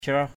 /crɔh/ (cv.) traoh _t<H 1.